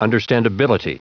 Prononciation du mot understandability en anglais (fichier audio)
Prononciation du mot : understandability